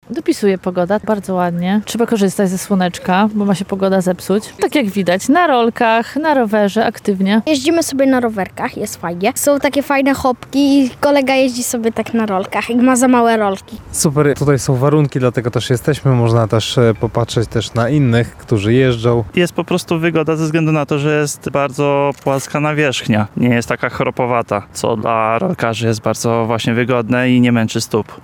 Mieszkańcy Lublina